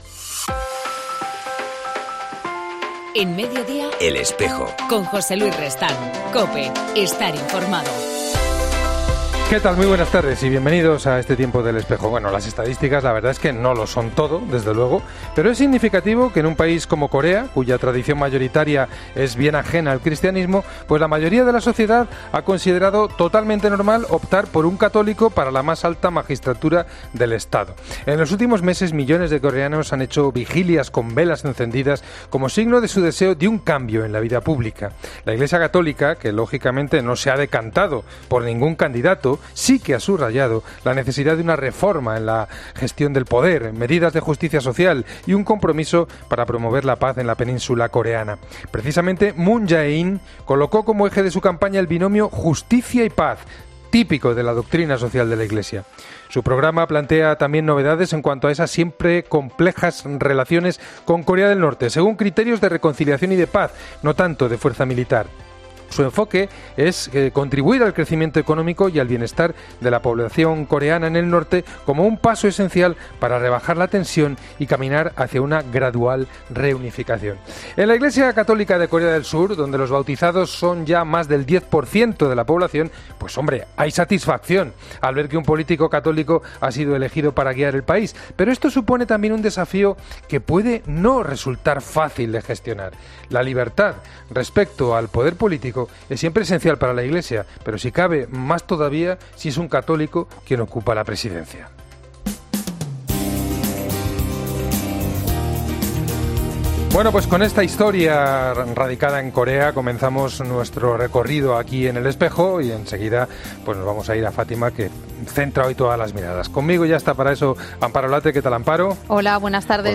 AUDIO: En El Espejo entrevistamos al Secretario General y Portavoz de la CEE, José María Gil Tamayo, para profundizar en el Mensaje del Comité...